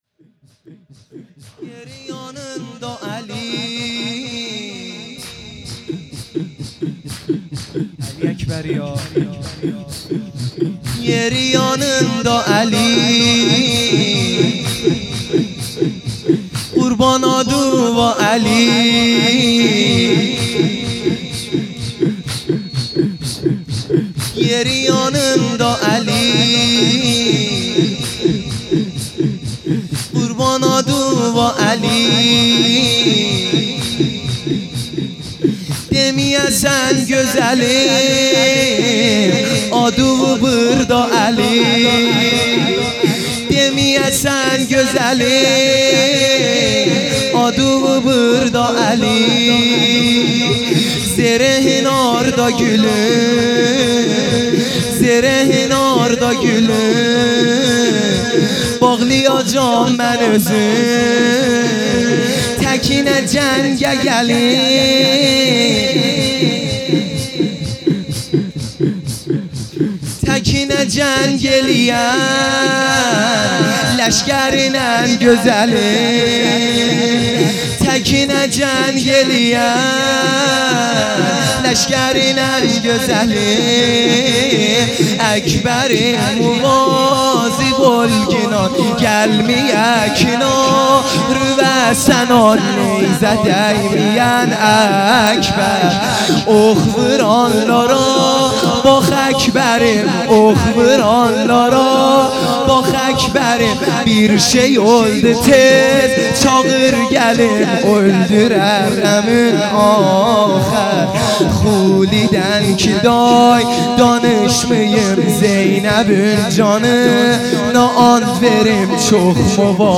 لطمه زنی | یری یانیمدا علی